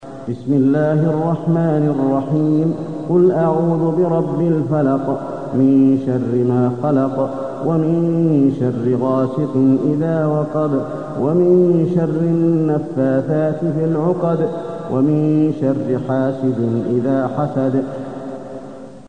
المكان: المسجد النبوي الفلق